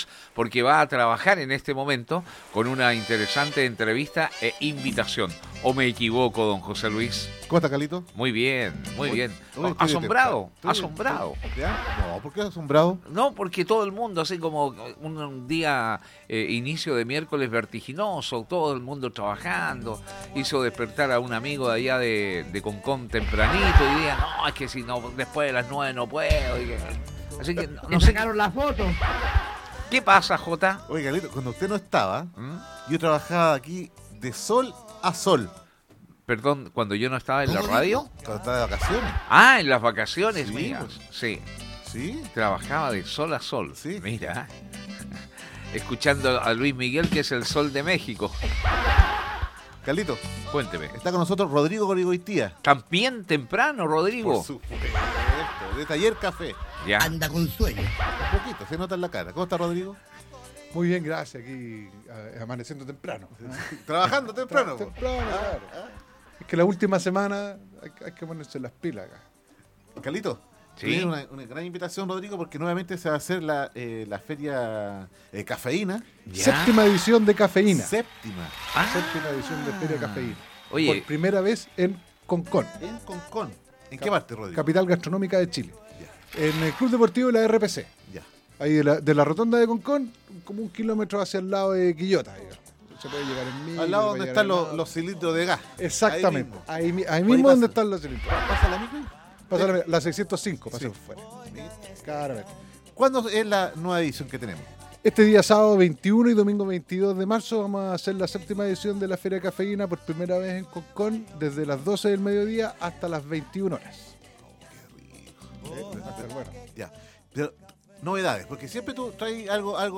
estuvo en los estudios de Radio Festival para invitar a todos a la séptima edición de la Feria Cafeyna que se realizará el próximo 21 y 22 de Marzo en el Club deportivo de la RPC